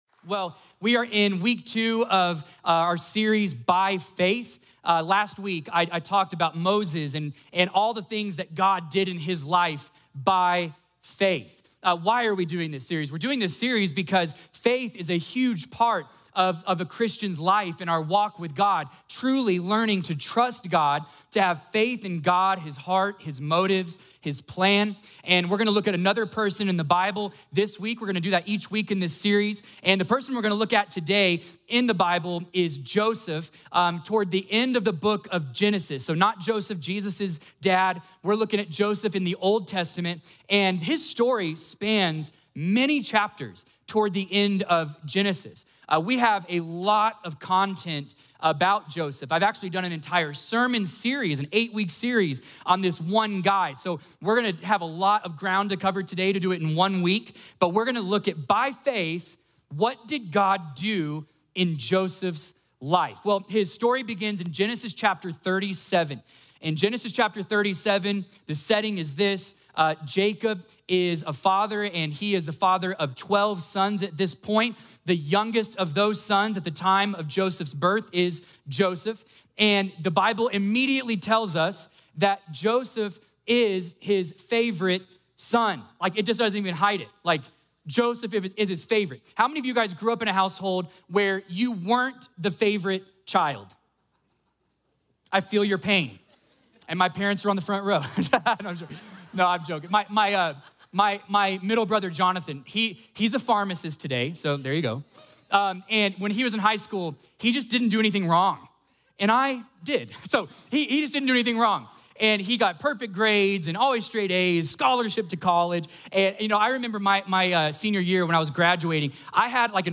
A message from the series "By Faith."